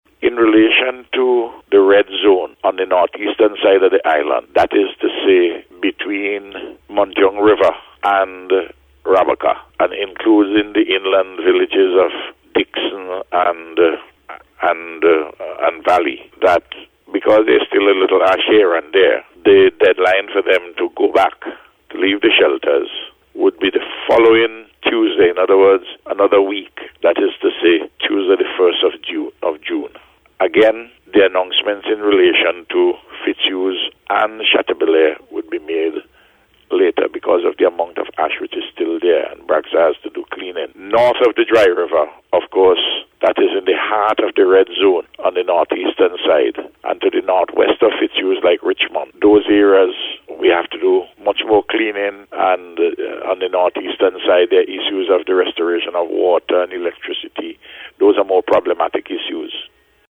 Word of this came from Prime Minister Dr. Ralph Gonsalves, during an interview with NBC News yesterday.